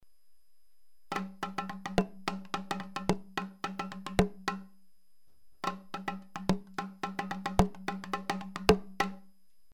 URM Sonos de Sardigna: nuovi strumenti - Tamburi in bambu
TamburiBambu.mp3